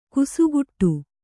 ♪ kusuguṭṭu